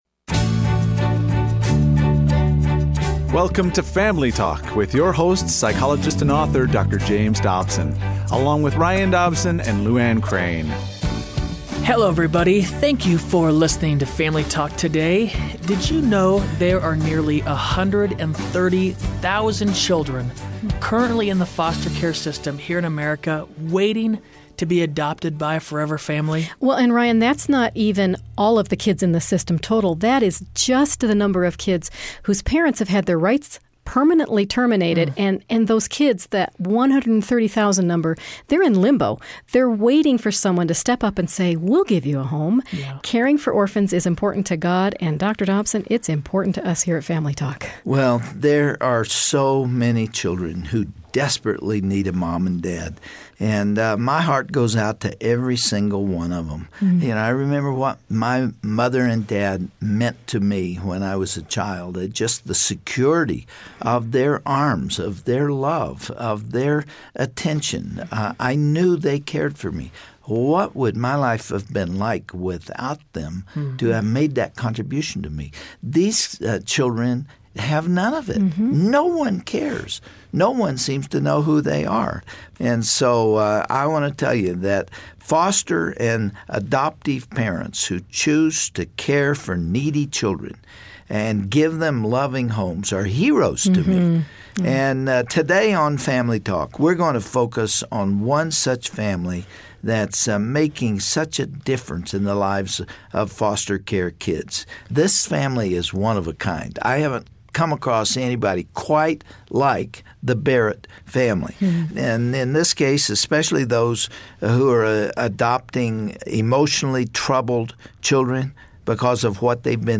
Host Dr. James Dobson